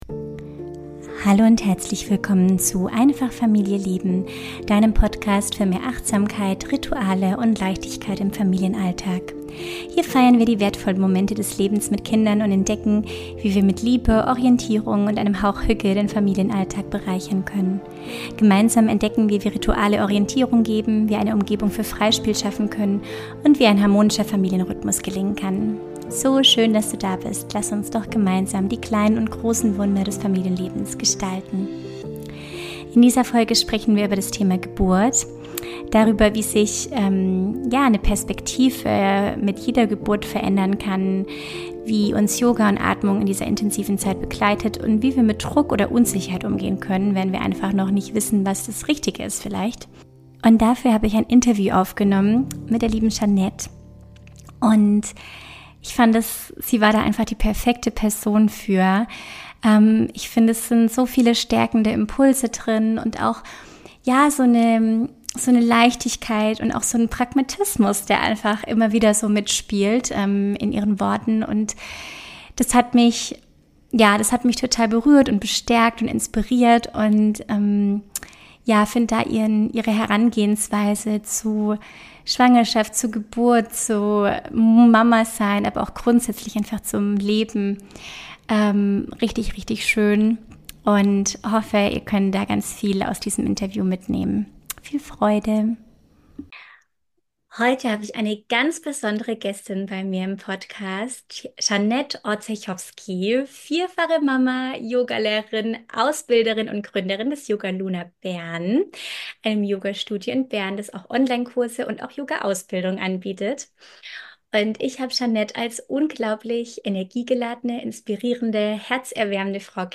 Wir sprechen darüber, wie Yoga und Atmung uns in der Schwangerschaft und Geburtsvorbereitung unterstützen können – und wie wir lernen, mit Unsicherheit, innerem Zweifel oder äußerem Druck umzugehen. Ein Gespräch über Körperwissen, Vertrauen, Mutterschaft und Verbindung.